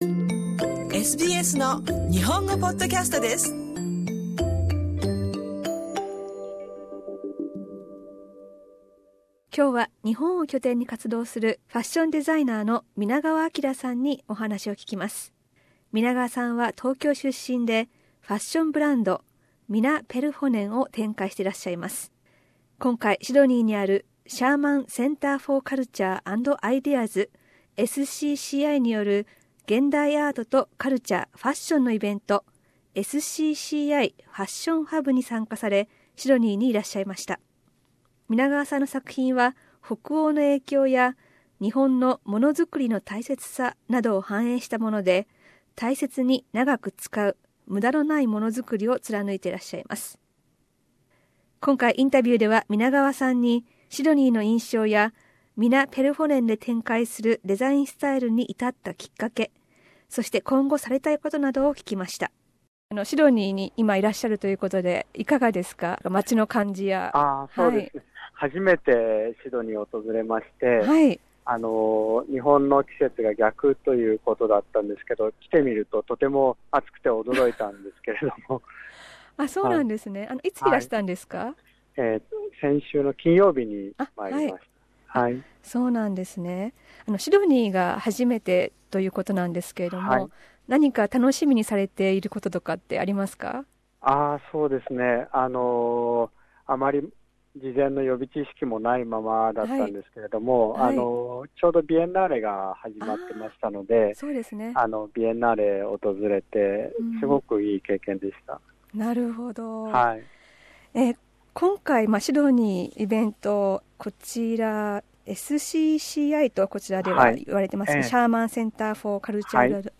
インタビューでは、シドニーの印象や現在のデザインスタイルに至ったきっかけ、今後したいことなどを聞きました。